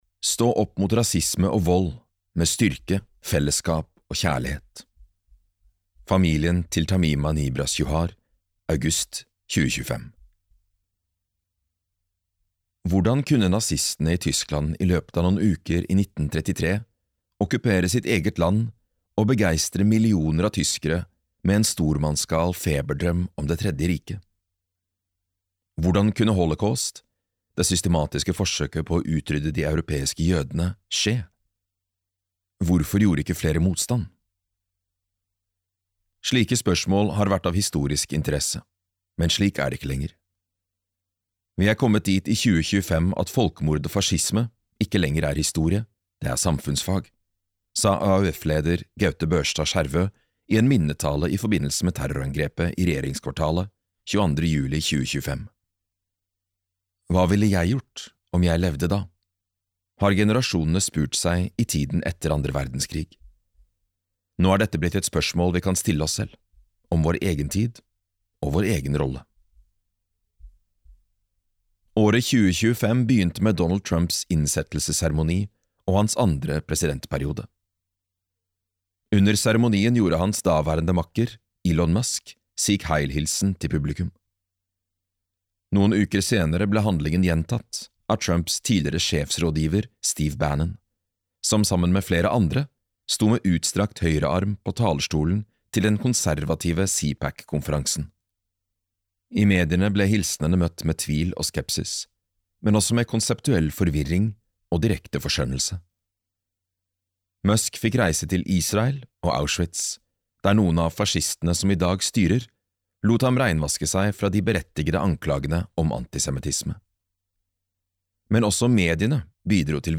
Sammen bekjemper vi fascismen - strategier for antifascister i dag (lydbok) av Jonas Bals